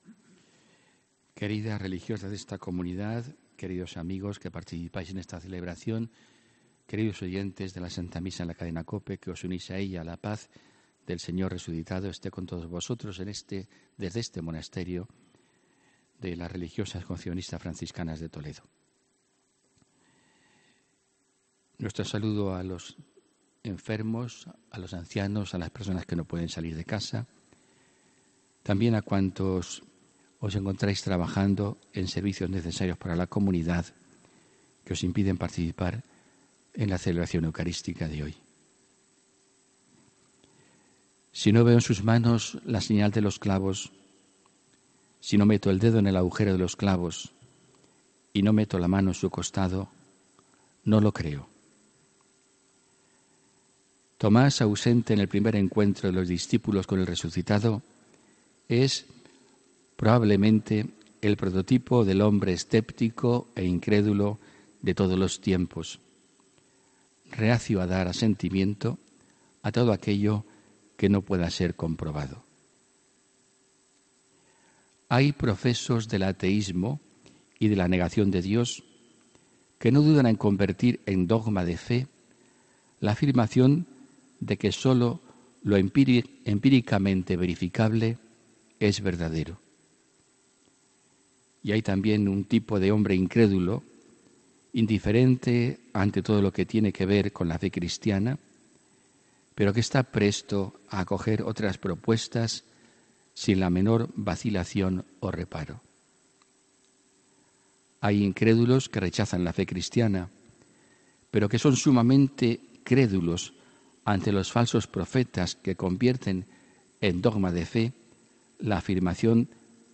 HOMILÍA 8 ABRIL 2018